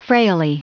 Prononciation du mot frailly en anglais (fichier audio)
Prononciation du mot : frailly